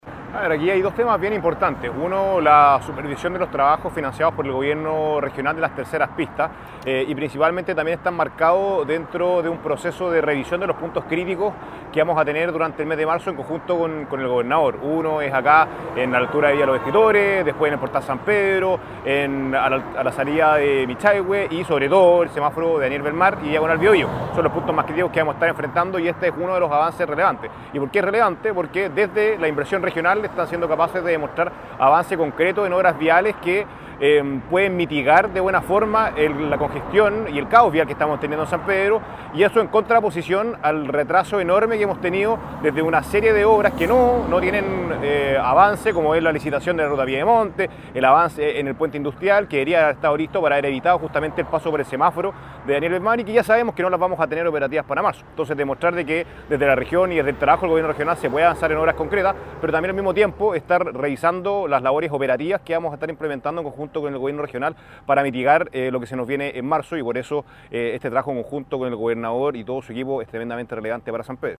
La máxima autoridad regional y el acalde de San Pedro de la Paz, Juan Pablo Spoerer, realizaron una visita inspectiva. Éste último se refirió a la relevancia de la obra.